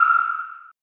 sonar1.mp3